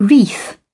Transcription and pronunciation of the word "wreath" in British and American variants.